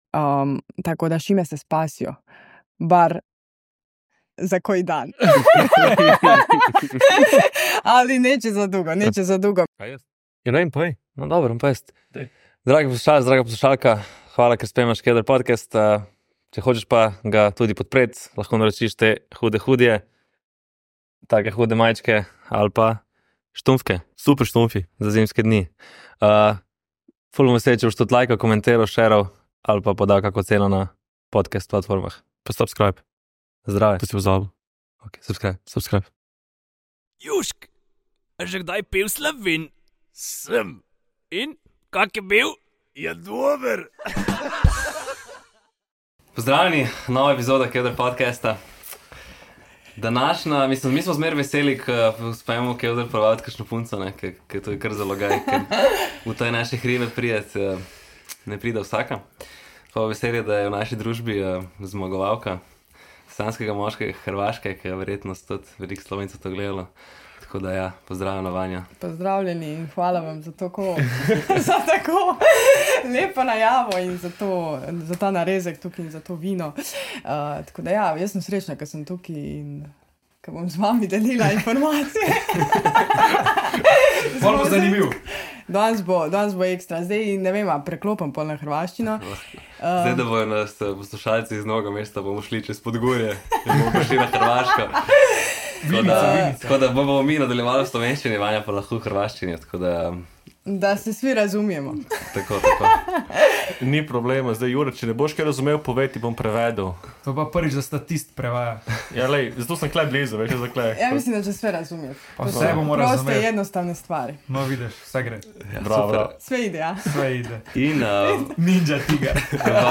Sašo v kevdru ponovno ni razočaral… tokrat smo govorili o cenzuri v filmih, na radiu in televiziji, o njegovem osebnem video arhivu in o njegovih težavah pri postavitvi bazena. Vmes pa je seveda postregel tudi z odličnimi imitacijami…Več pa v podkastu!